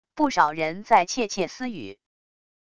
不少人在窃窃私语wav音频